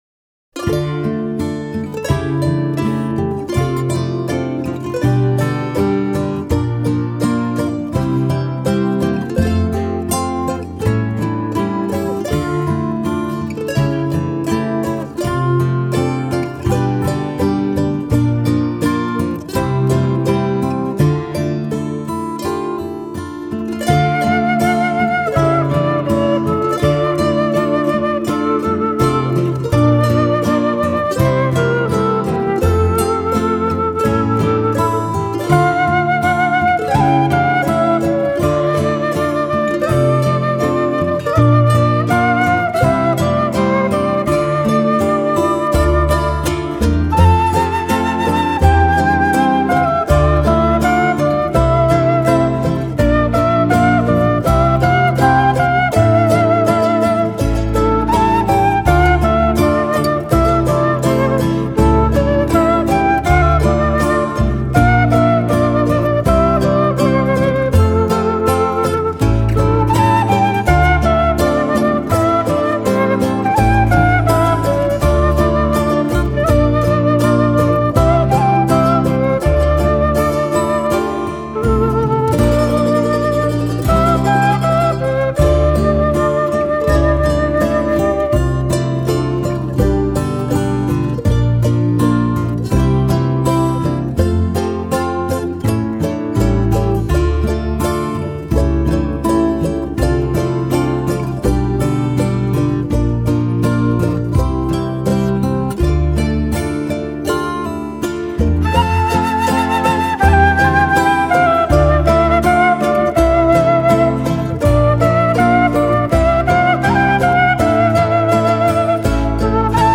南美长笛+结他+键盘。